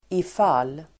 Uttal: [if'al:]